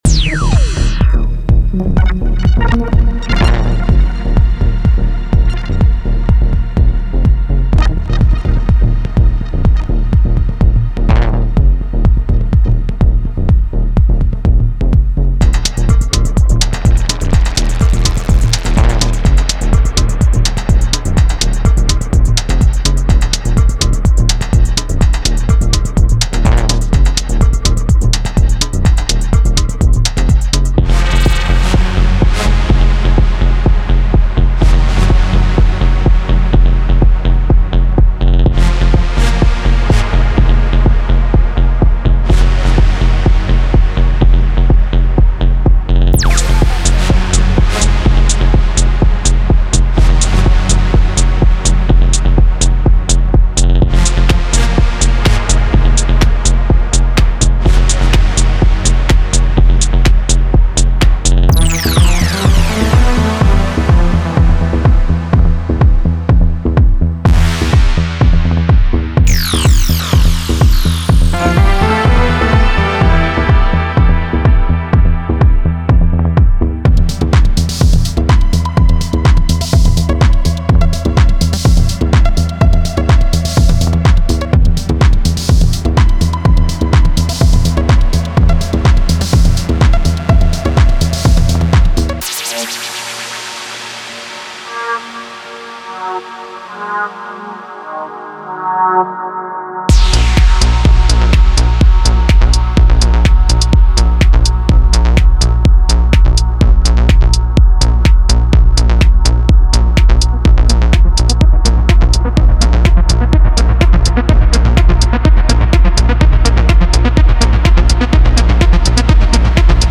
これらのサンプルを組み合わせることで、荒廃と未来主義の感覚を呼び起こします。
デモサウンドはコチラ↓
Genre:Minimal Techno